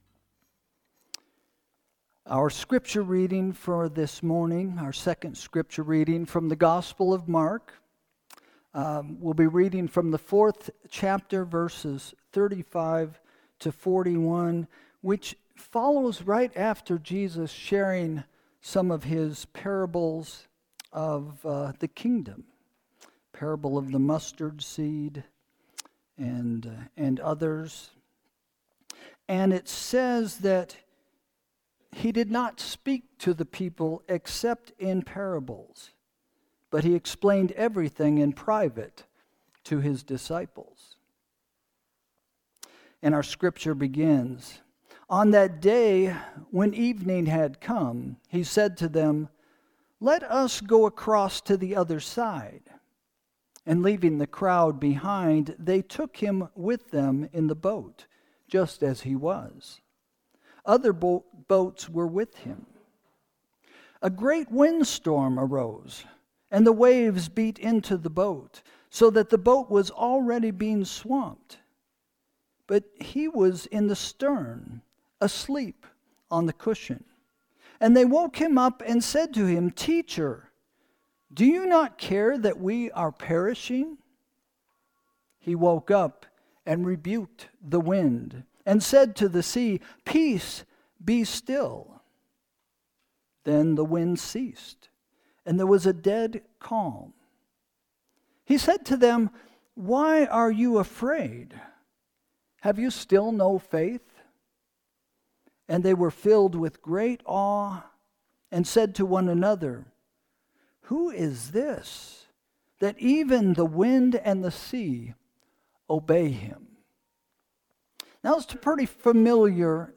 Sermon – November 2, 2025 – “Fear of Freedom”